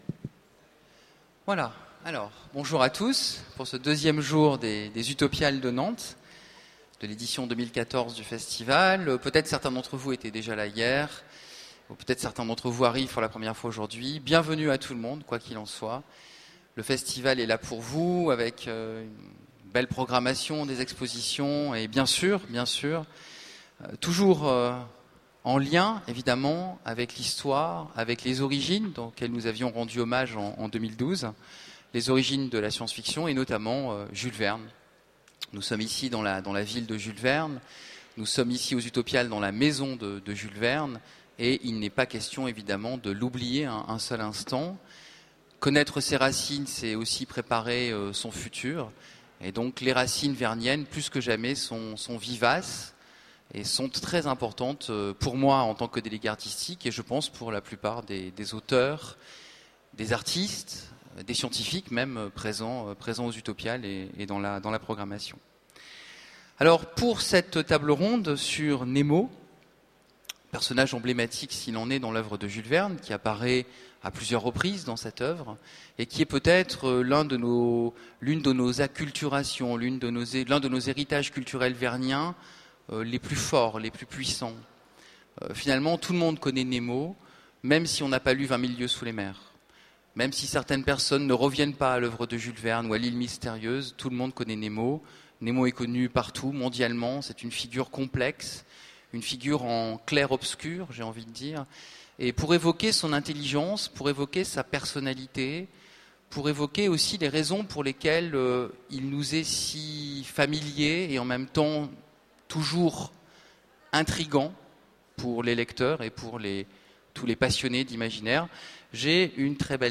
Mots-clés Jules Verne Conférence Partager cet article